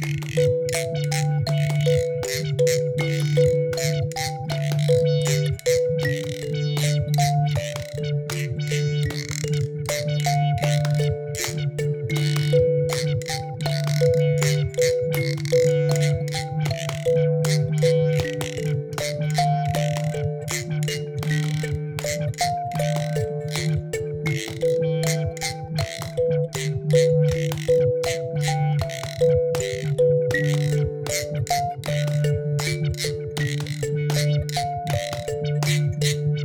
Claps
Drone
Guiro
Kalimba